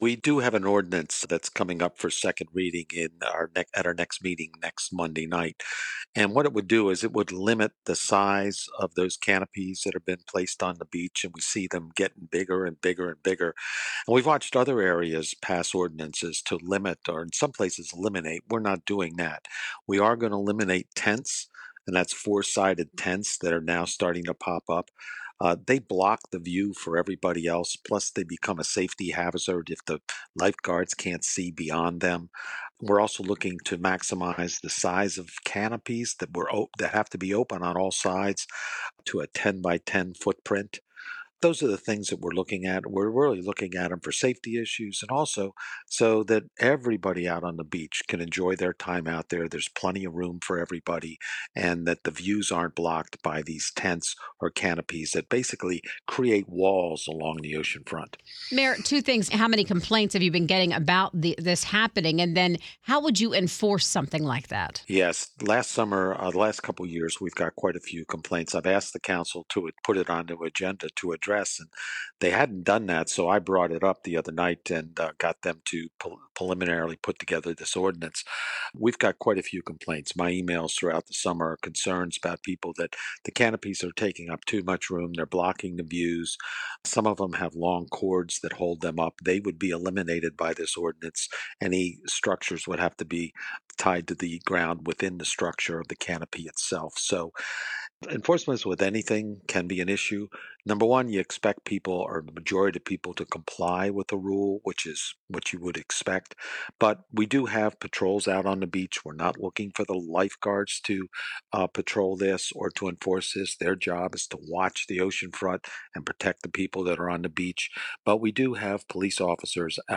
Ocean City, Maryland, Mayor Rick Meehan joins